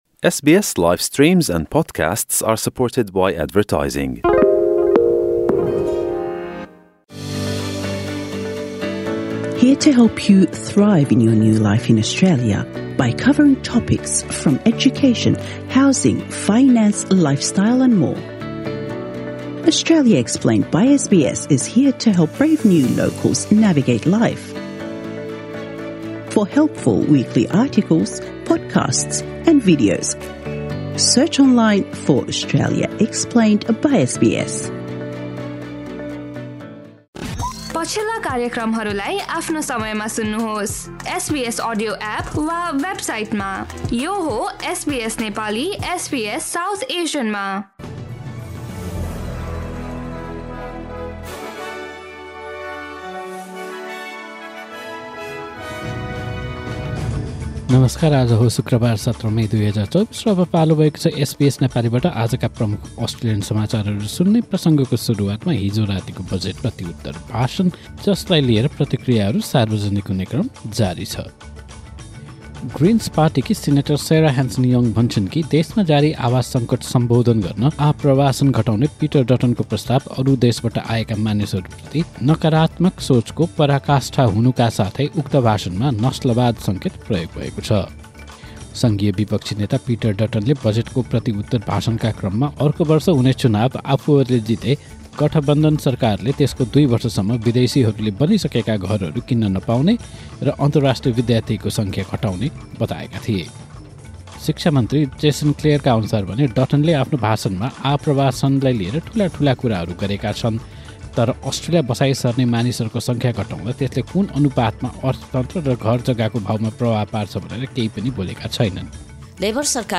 SBS Nepali Australian News Headlines: Friday, 17 May 2024